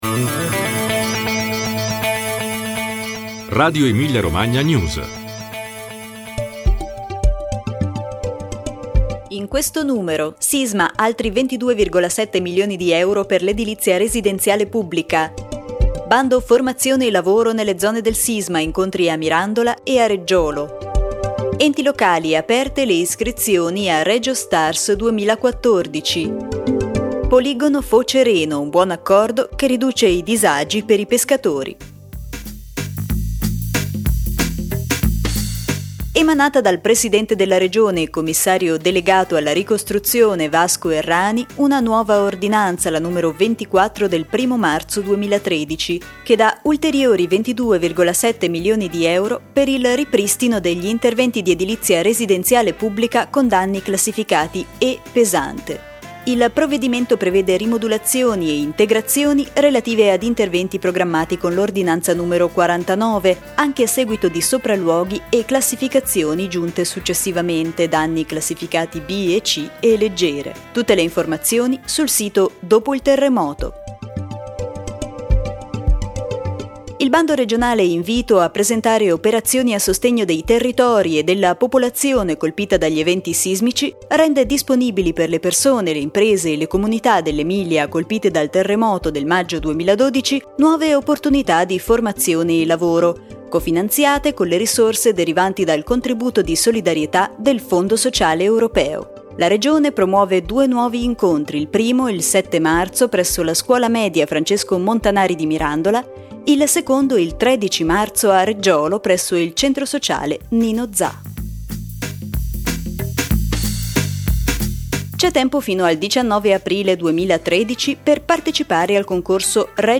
Notizie dalla Regione e dal mondo